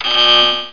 alert.mp3